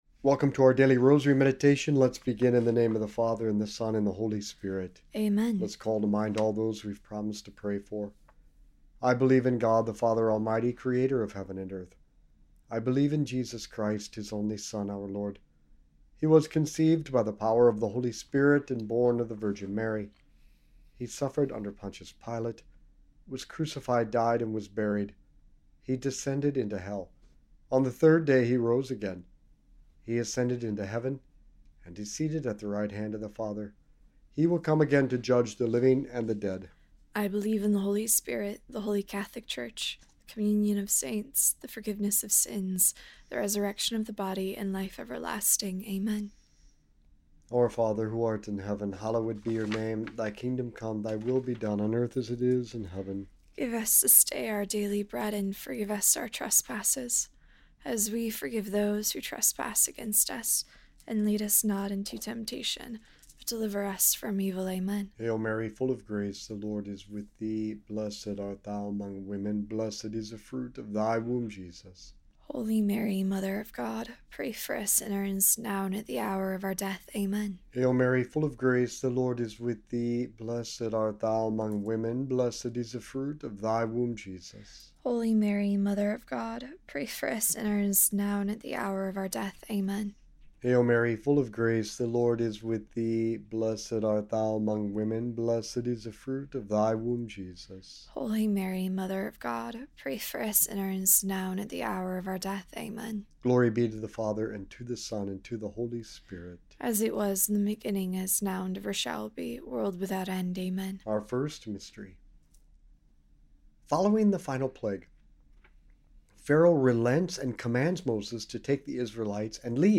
This episode presents a Catholic rosary meditation centered on the biblical story of Pharaoh's hardened heart during the plagues of Egypt.